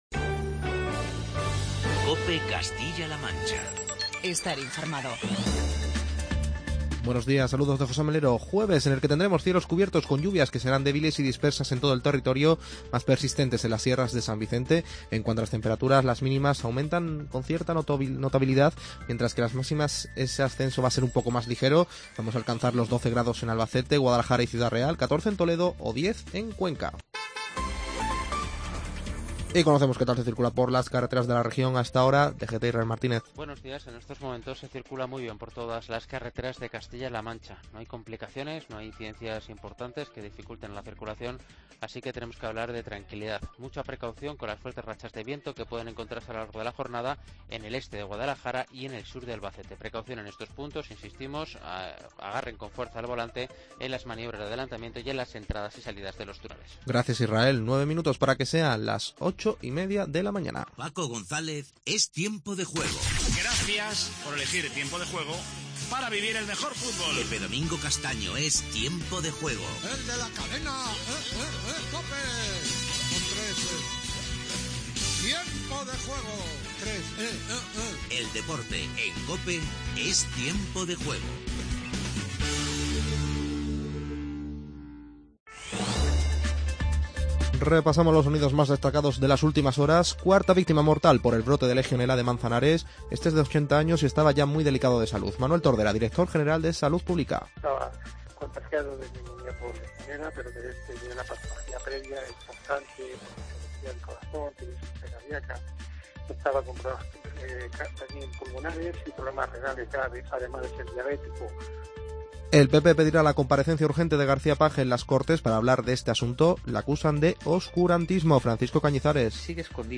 Informativo regional y provincial
Repasamos los sonidos más destacados de las últimas horas.